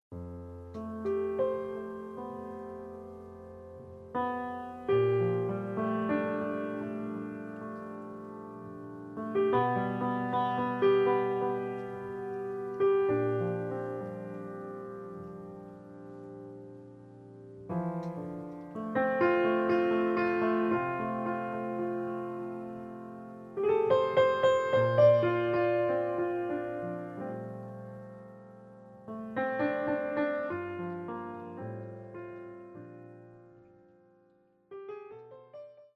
sax
guitar
piano
bass
drums